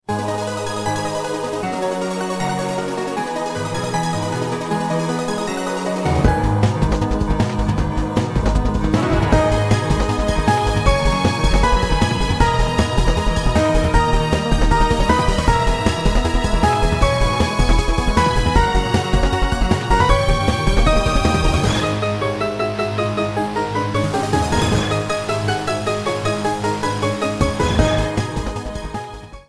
Ripped from the remake's files
trimmed to 29.5 seconds and faded out the last two seconds